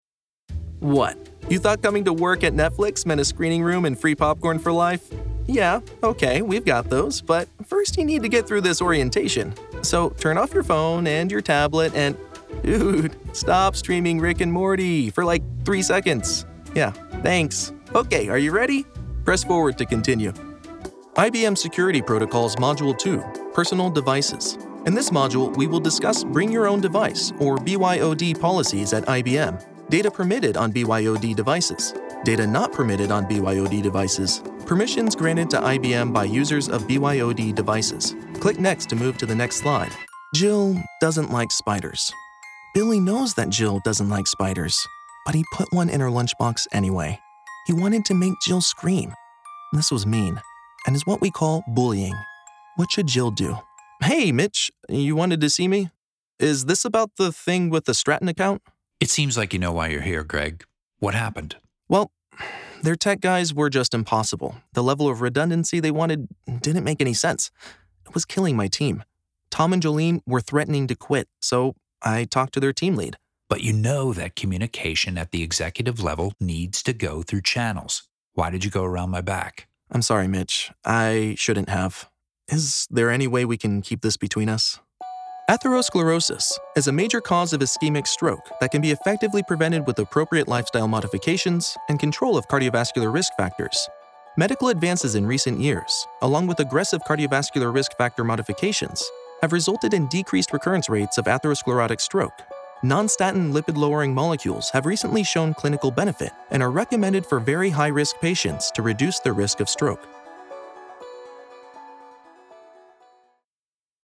eLearning: Conversational, neutral, friendly
Tutorial, Narrative